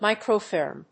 アクセント・音節mícro・fòrm